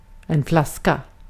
Uttal
Synonymer pava butelj putell (slang) flarra Uttal Okänd accent: IPA: /ˈflàska/ Ordet hittades på dessa språk: svenska Översättning 1. şişe Artikel: en .